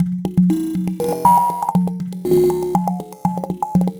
FM Viva Perc.wav